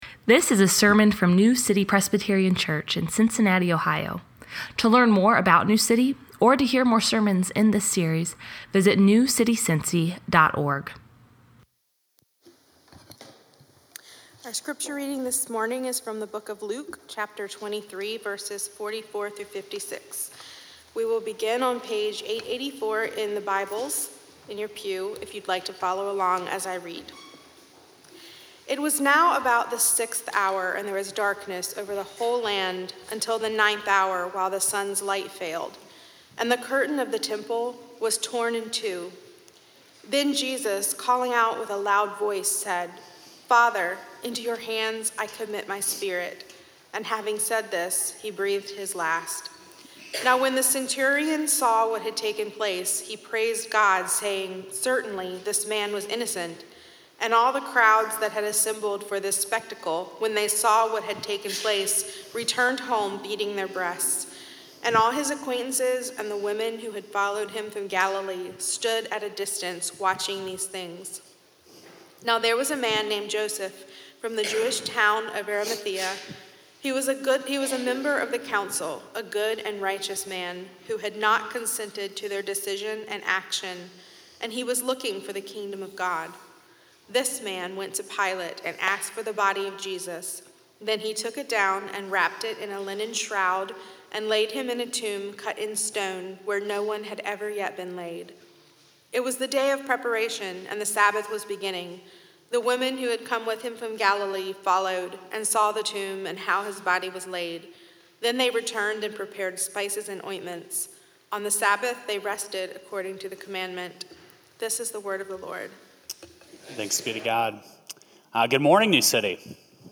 Sermons from New City Presbyterian Church: Cincinnati, OH